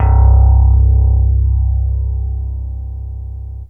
SYNTH BASS-2 0018.wav